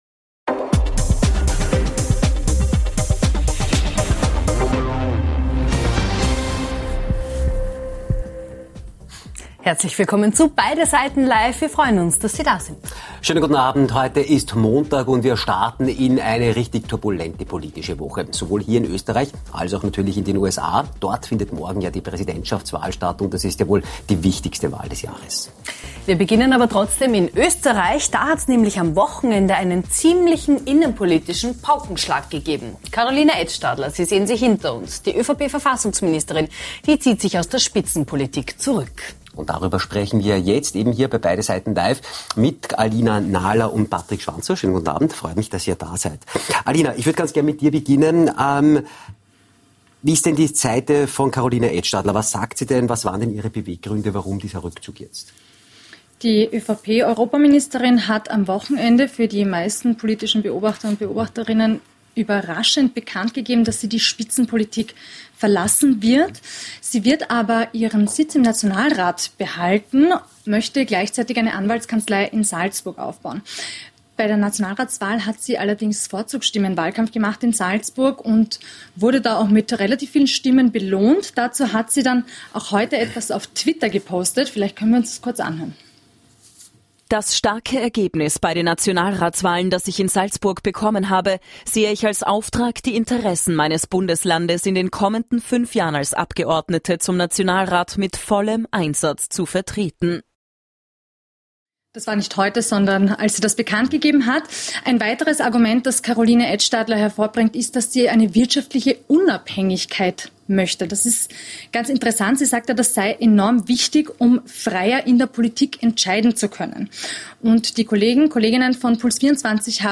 Und nachgefragt haben wir heute bei gleich zwei Gästen - Im großen Beide Seiten Live Duell mit einem der führenden FPÖ-Ideologen und Ex-FPÖ-Politiker Andreas Mölzer und dem ehemaligen ÖVP-Landeshauptmann von Salzburg Franz Schausberger.